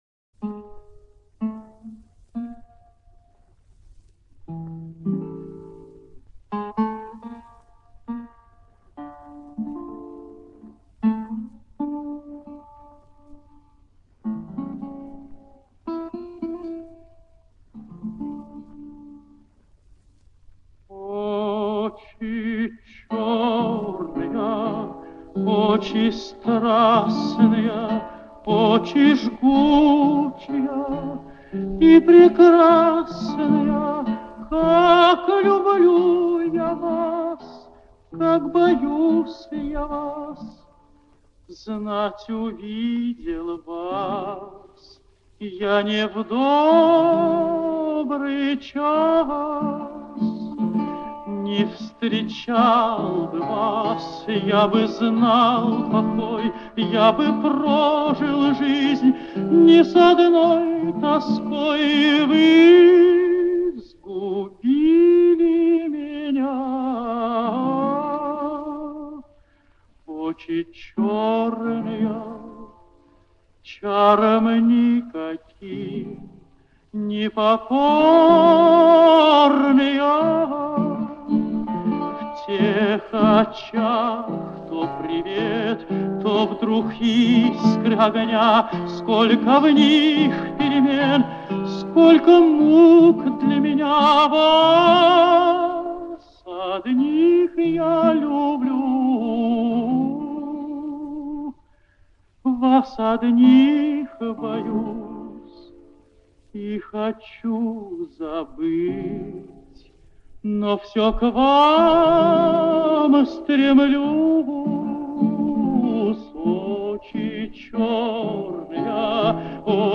старинный русский романс